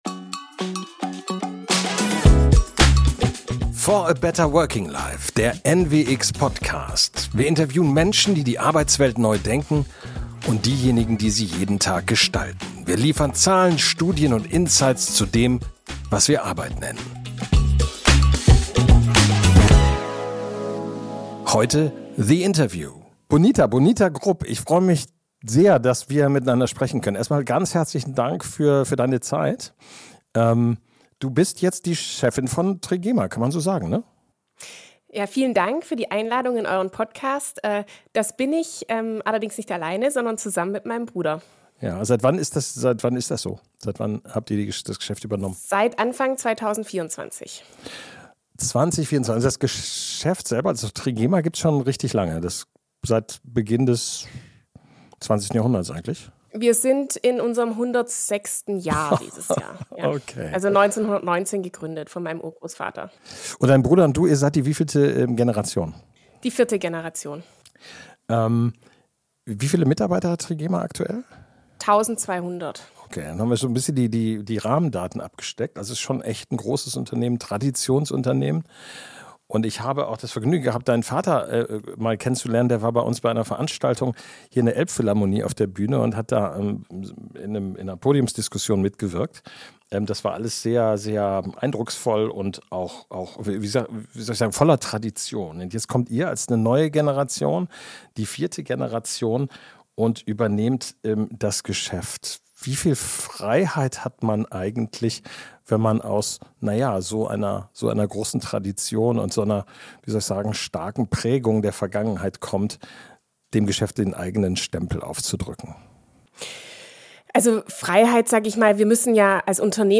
… continue reading 200 епізодів # Karriere # Business # Bildung # Newwork # Zukunft # Arbeit # Work # Future # Gesellschaft # Selbstentwicklung # NEW WORK SE # Working # Interview # Briefingsa # Trends